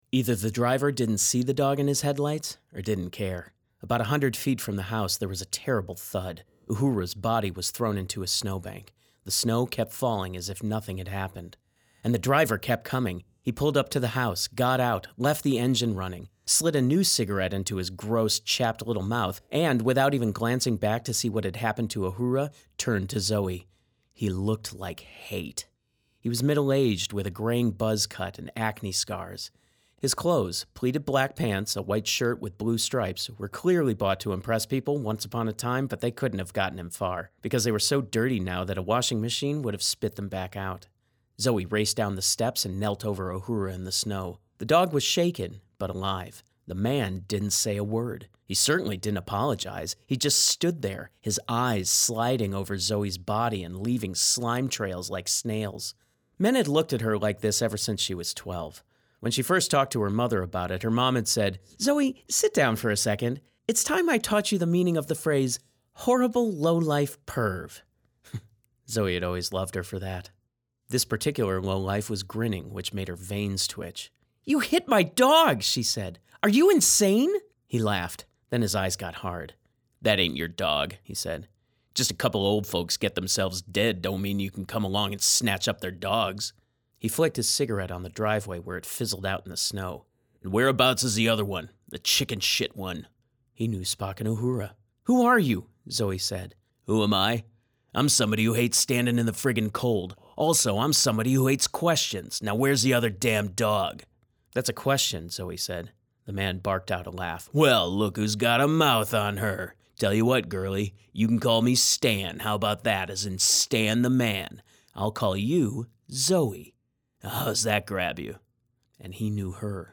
Audiobook
English - Midwestern U.S. English